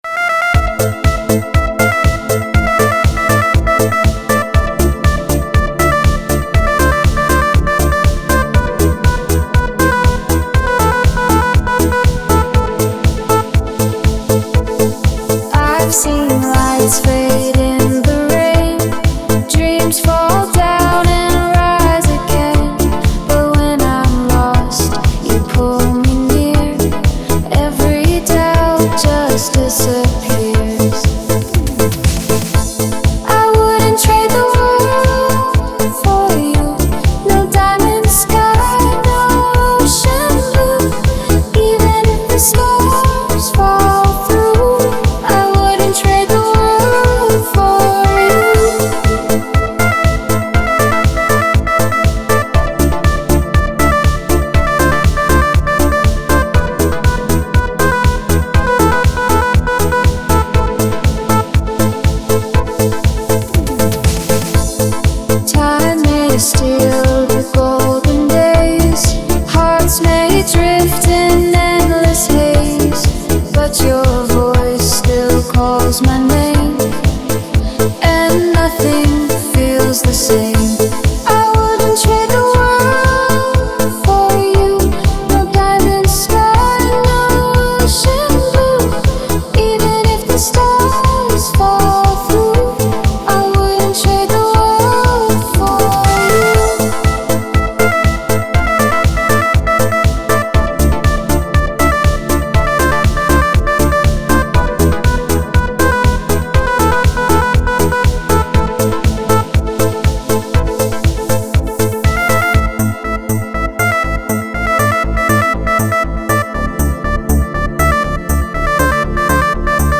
танцевальная музыка
pop
диско , dance